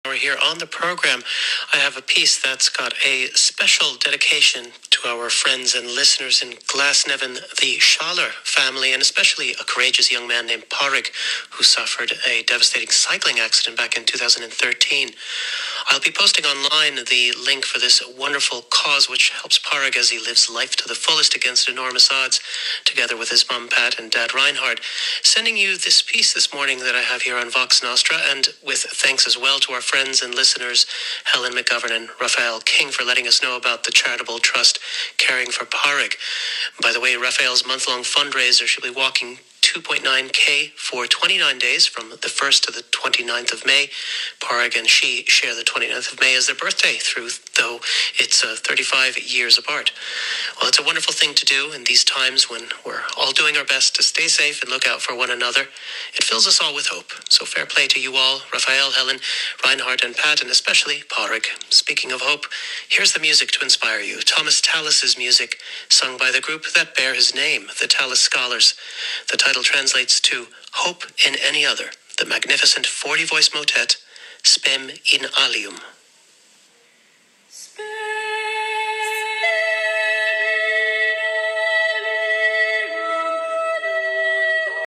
RTÉ Lyrics FM radio programme